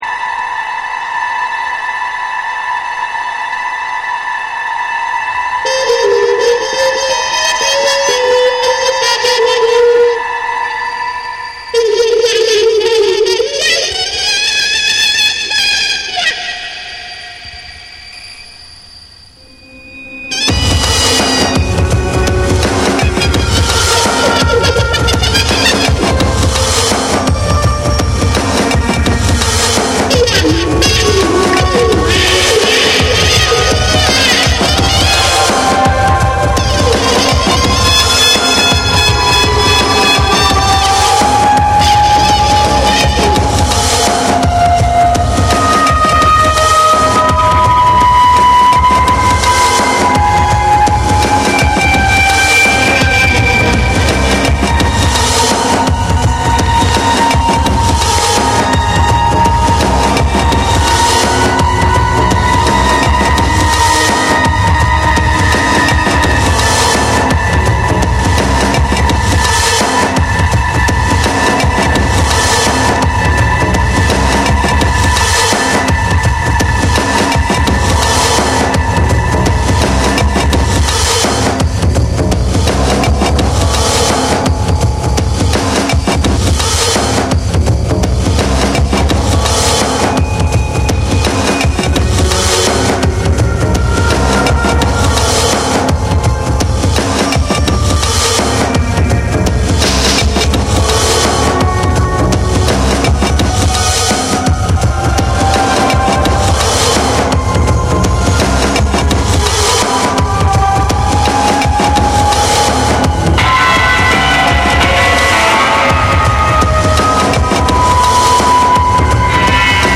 ジャズやアンビエントが交錯する実験的なビートダウンを展開し、ドープかつ幻想的な質感が全編に漂うブレイクビーツを収録。
BREAKBEATS